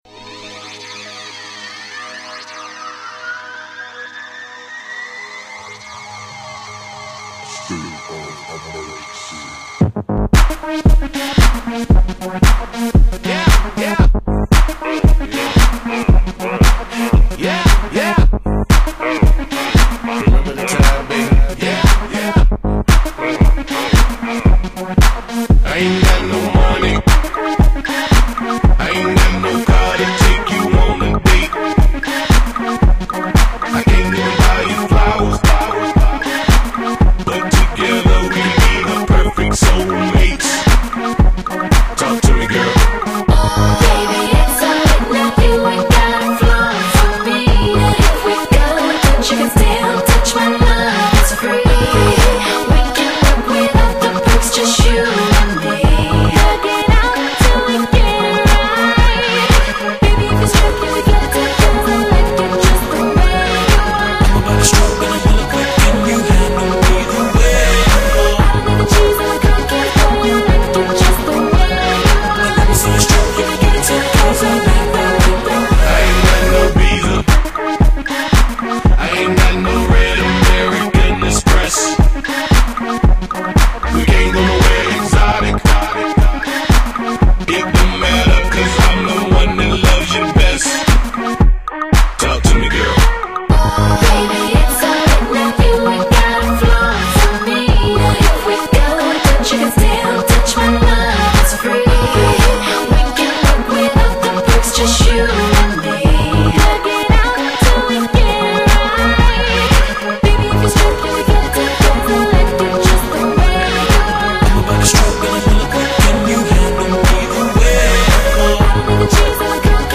Жанр:Hip-Hop,Rap,R&B,Pop,Rock...